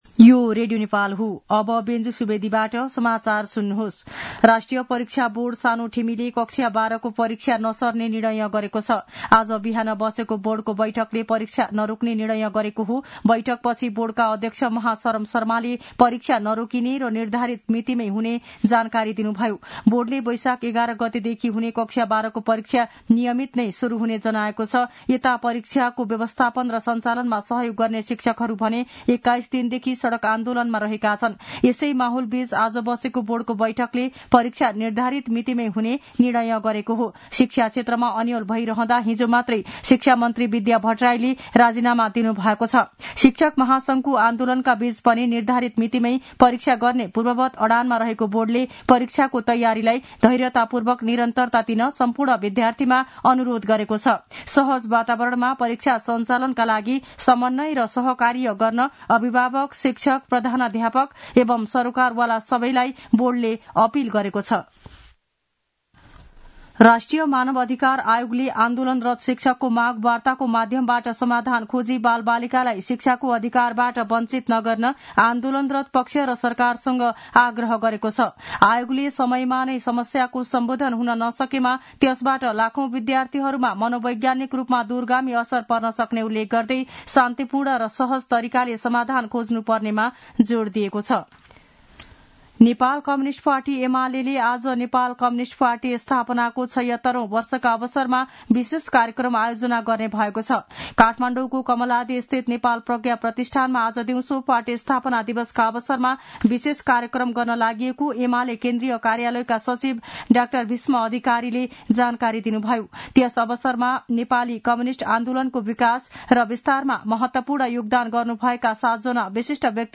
दिउँसो १ बजेको नेपाली समाचार : ९ वैशाख , २०८२